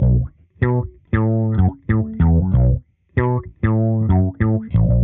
Index of /musicradar/dusty-funk-samples/Bass/95bpm